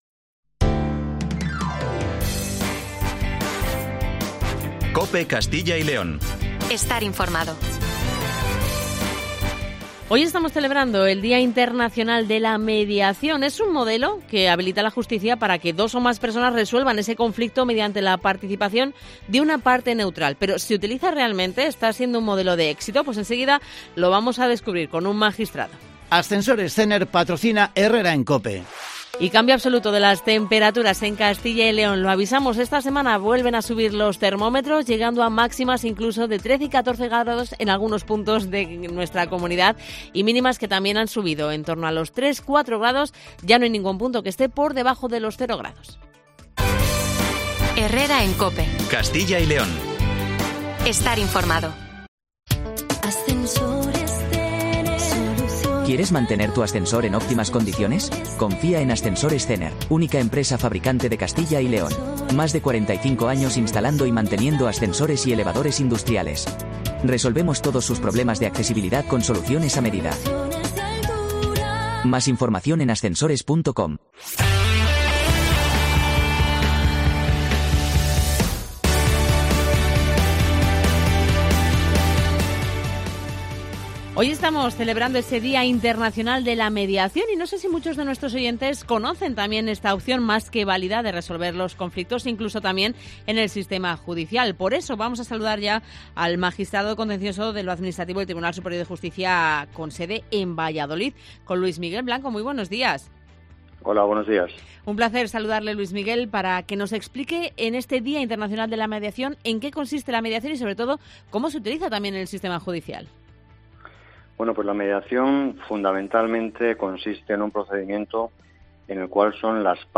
Hoy es el Día Internacional de la Mediación y conocemos con el magistrado Luis Miguel Blanco el funcionamiento de este proceso en el sitema judicial.